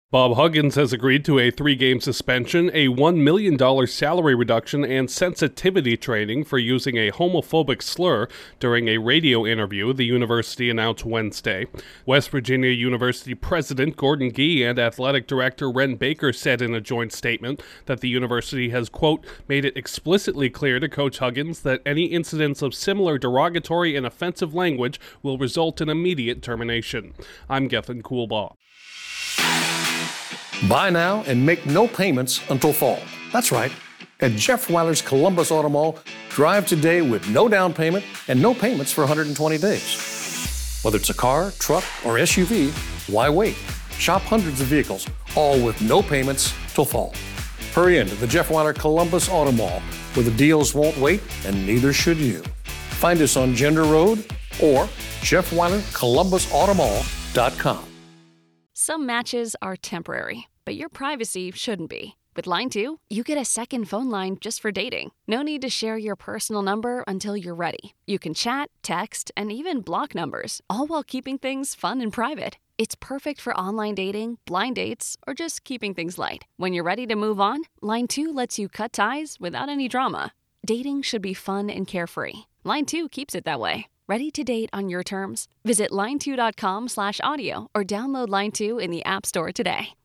A notable college basketball coach has been suspended and fined over controversial comments. Correspondent